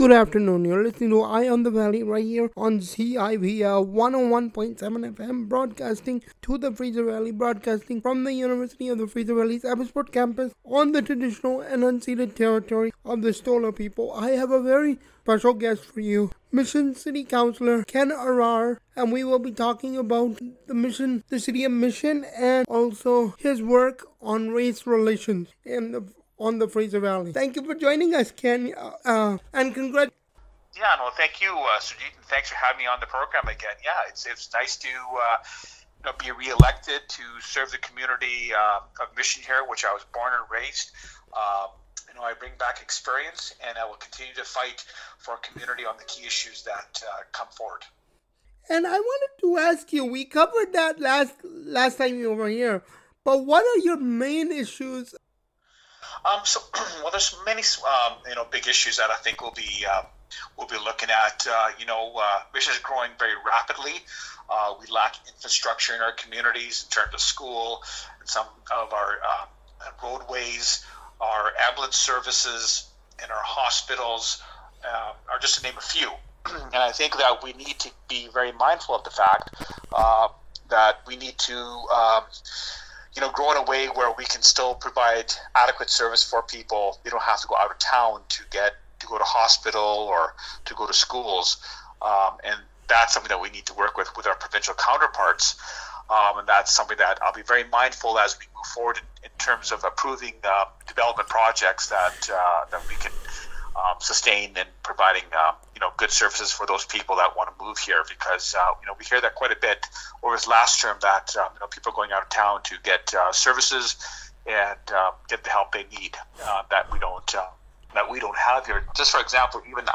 ken-interview-part-2.mp3